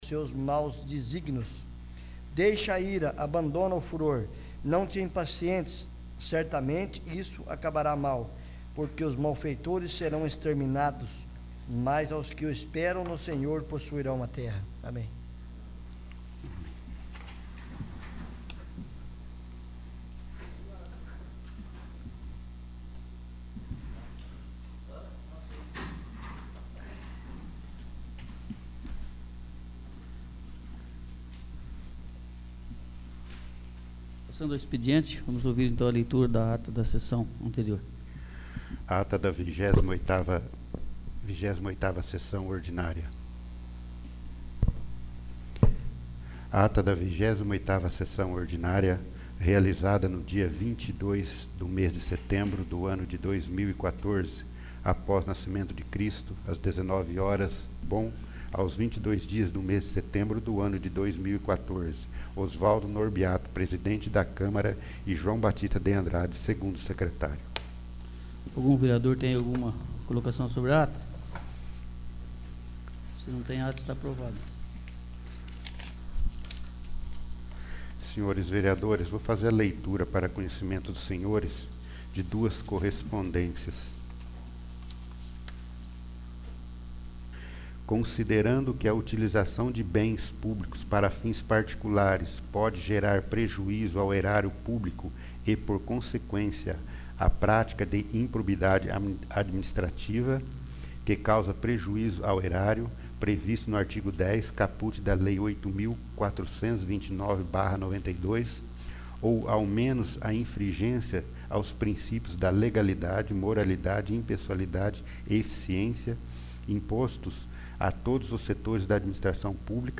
29º. Sessão Ordinária